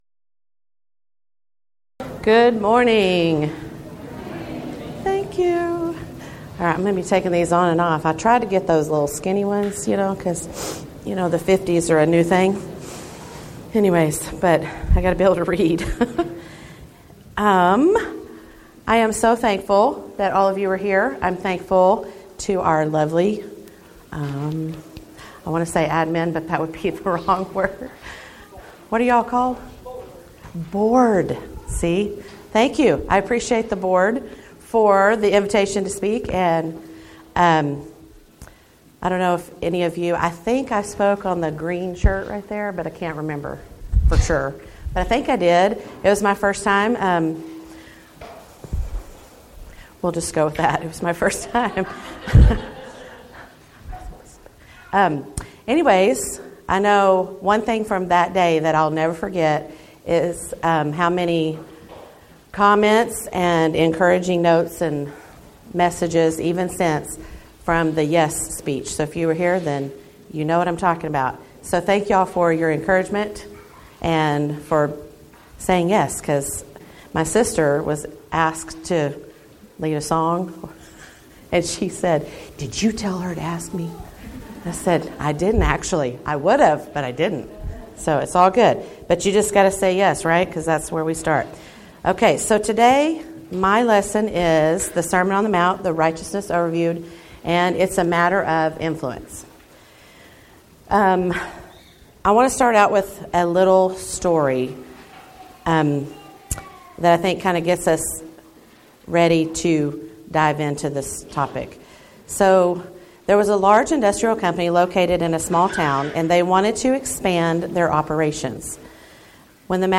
Event: 10th Annual Texas Ladies in Christ Retreat
Ladies Sessions